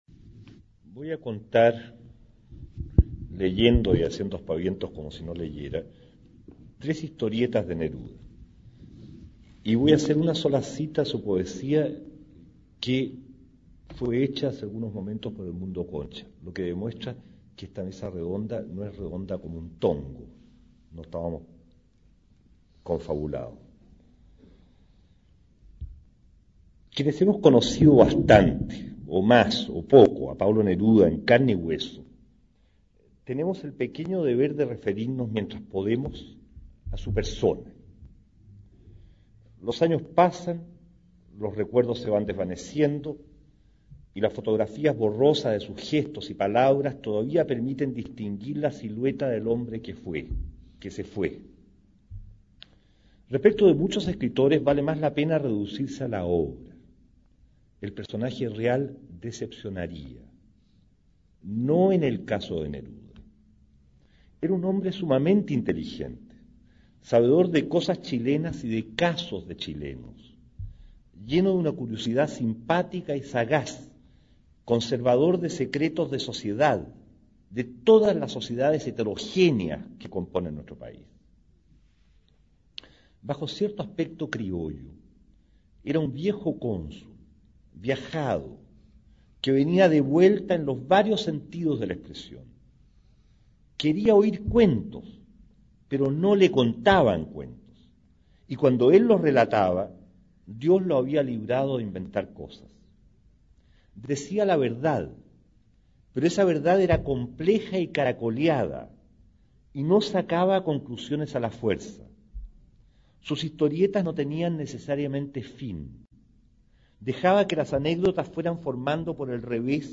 Aquí podrás escuchar una conferencia del poeta Armando Uribe, referida a la personalidad de Pablo Neruda. Apelando a sus recuerdos y propia experiencia con el autor, entrega aspectos privados del gran poeta nacional, muy útiles para conocerlo y aproximarse con mayor interés a su obra.
Conferencia